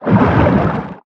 Sfx_creature_pinnacarid_swim_slow_05.ogg